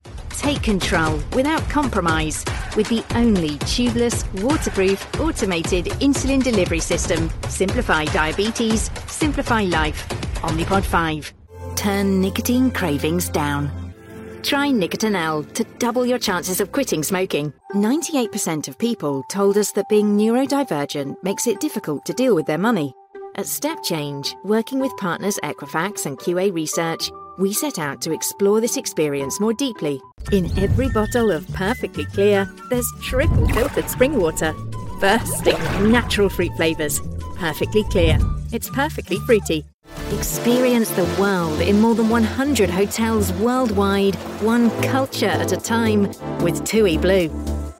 Adult (30-50) | Yng Adult (18-29)